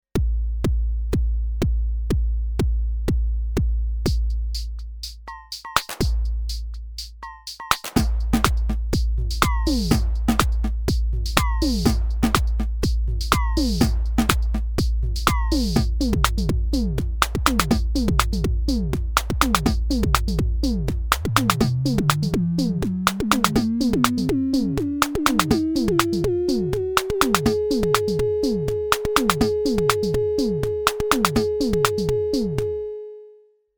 Hardware Drum Machines
Older digital units can be bought for under £50, and even if they don’t have the cachet or sonic character of a DMX or LinnDrum they can be great for adding extra flavour.